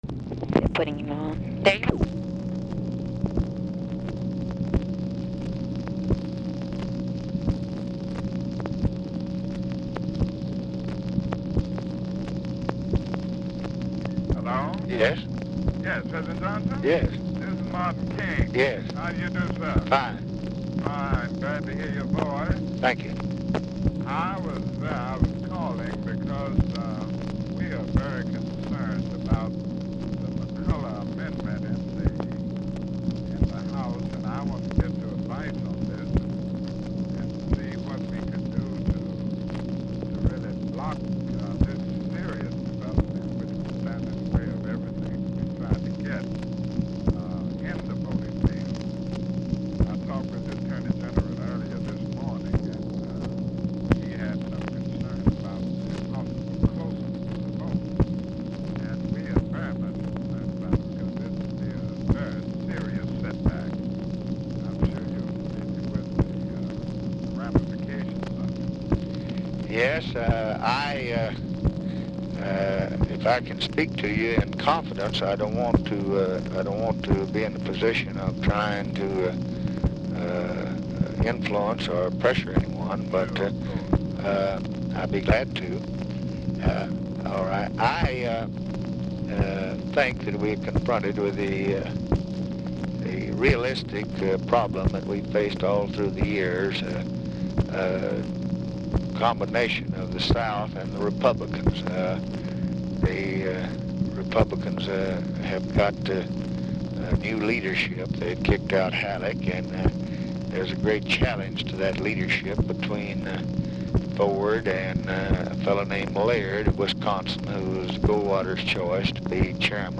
Telephone conversation # 8311, sound recording, LBJ and MARTIN LUTHER KING, 7/7/1965, 8:05PM | Discover LBJ
MLK ON HOLD 0:18, DIFFICULT TO HEAR
Format Dictation belt
Location Of Speaker 1 Oval Office or unknown location